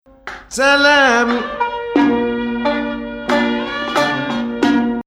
Rast 8
resolution of #14